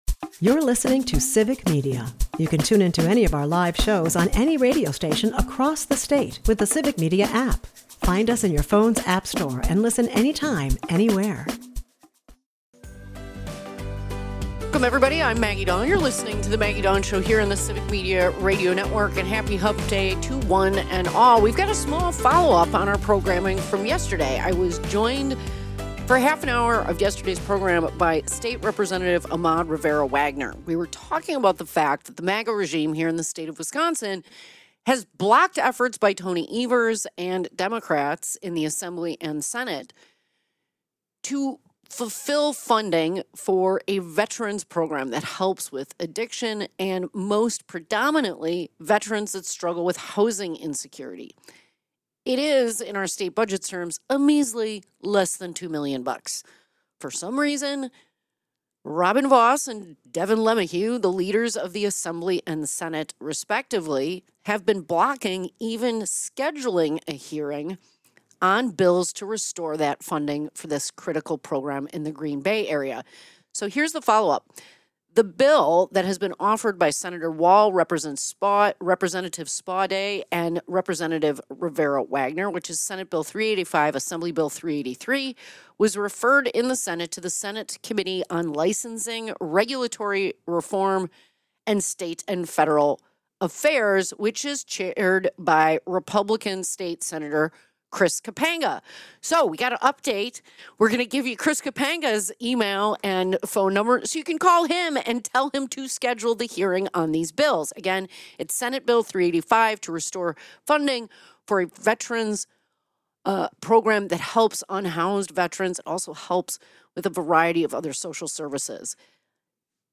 With urgency and conviction, she challenges all of us to stand up against policies that endanger both education and community well-being.